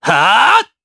Zafir-Vox_Attack4_jp.wav